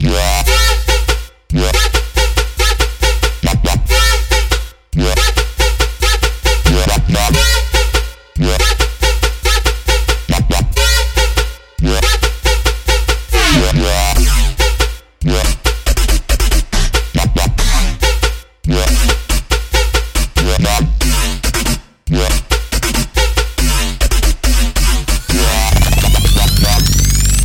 跌落7 肥沃的摇摆
描述：带着肥厚的低音
Tag: 140 bpm Dubstep Loops Bass Wobble Loops 4.62 MB wav Key : Unknown